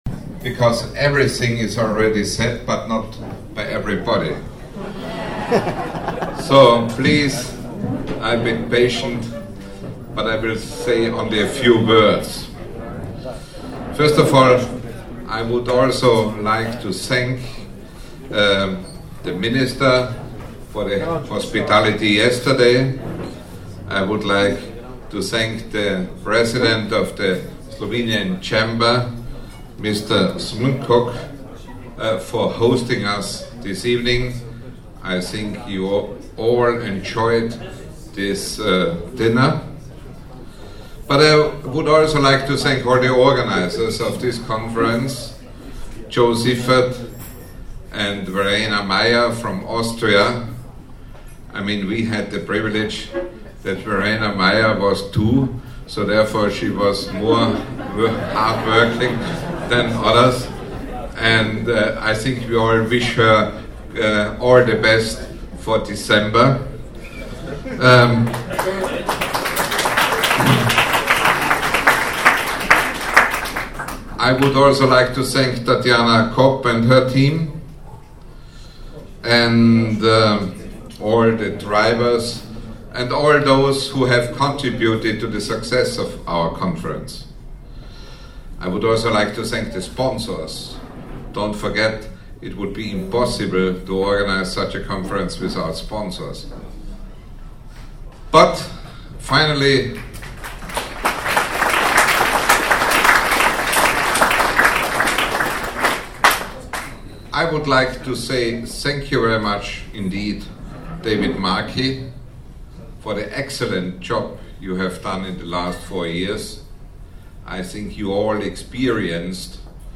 Franz FischlerI like the way Fran Fischler, President of the IFAJ Congress 2008, started his remarks at the end of the event.